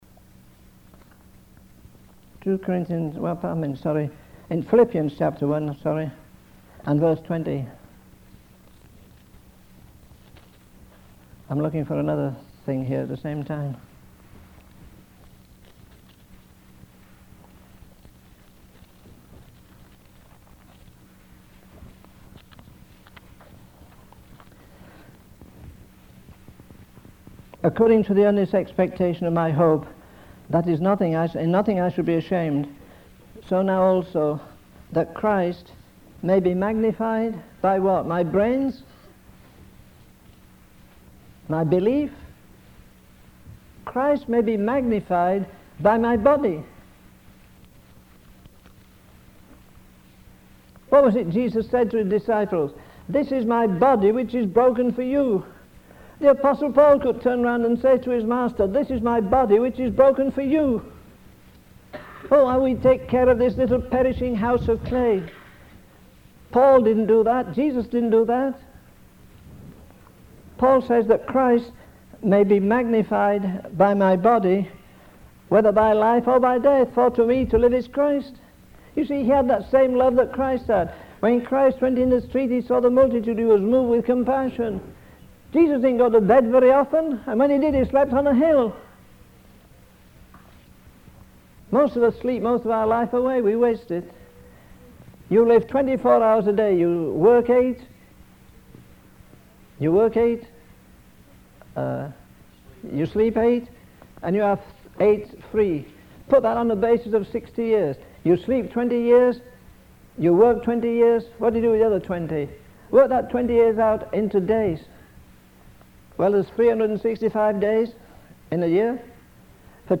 In this sermon, the preacher emphasizes the importance of dedicating our bodies to the Lord.